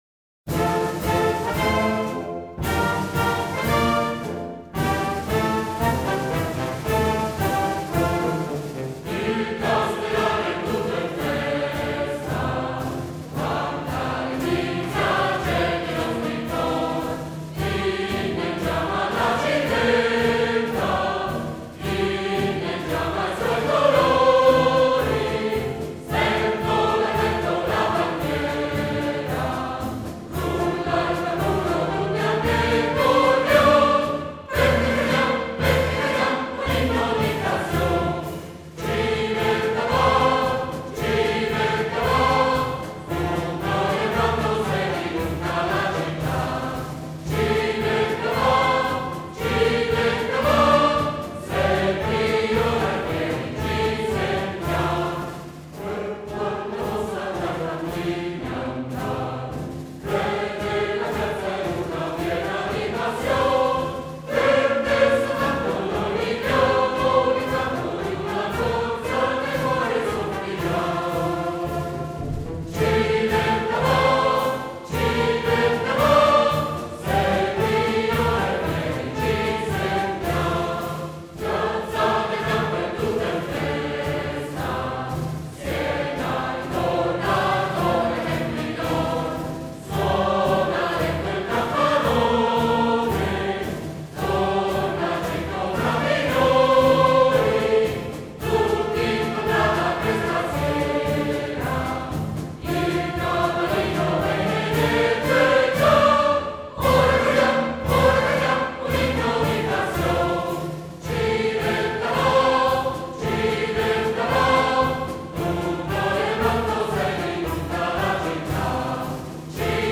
Versi e musica del Maestro Salvatore Cintorino – E’ un “marciabile” dalla struttura lineare e dal piglio volutamente popolare e contradaiolo.